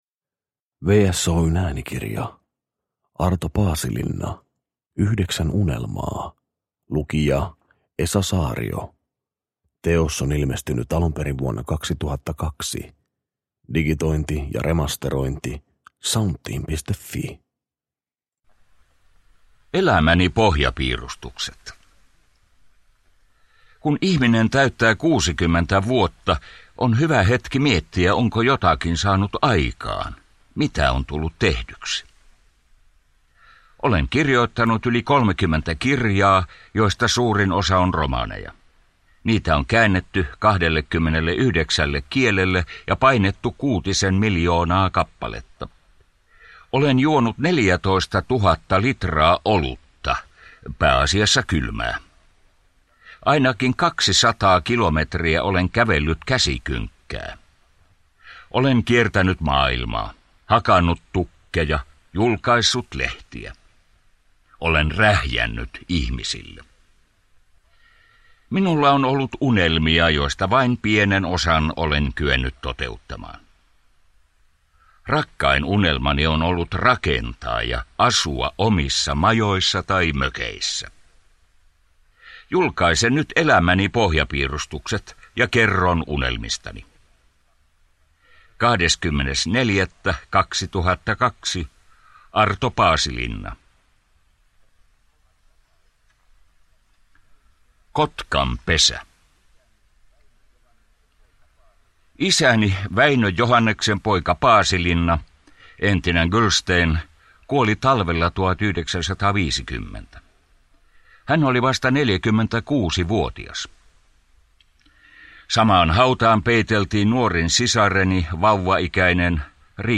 Yhdeksän unelmaa – Ljudbok